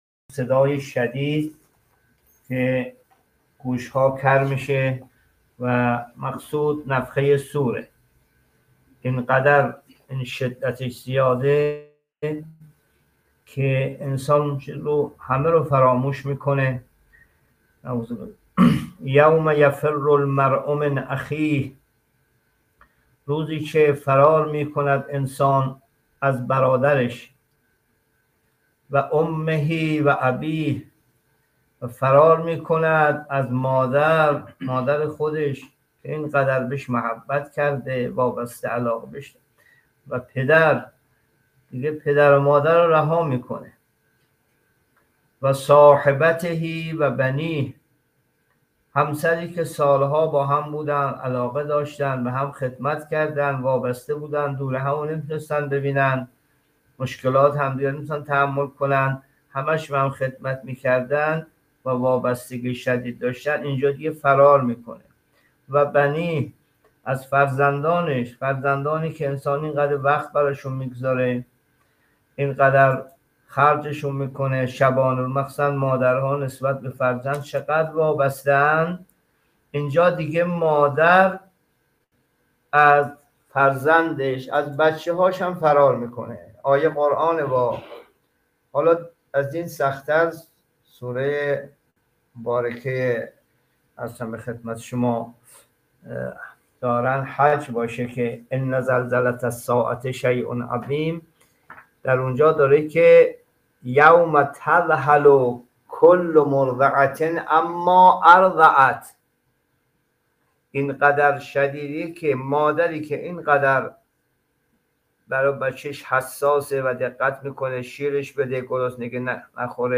جلسه تفسیر قرآن (۱۰) سوره عبس